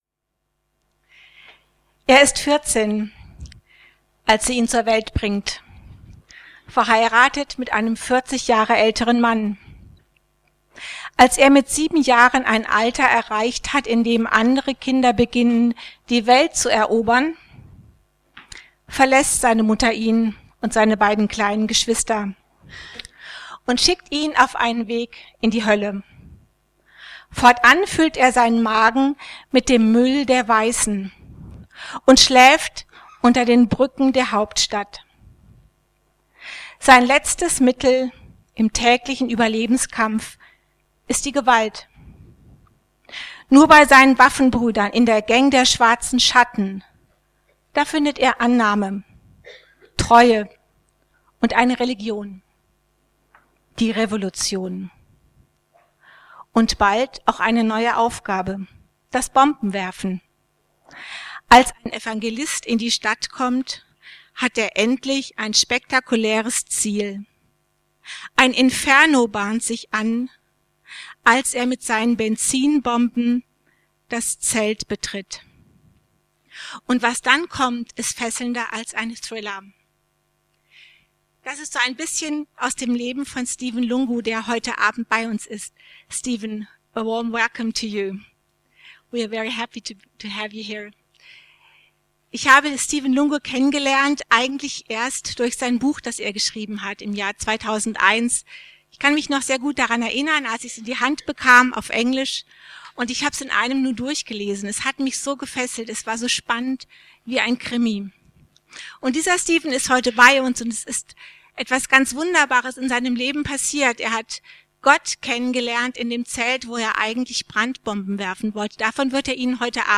englisch mit Übersetzung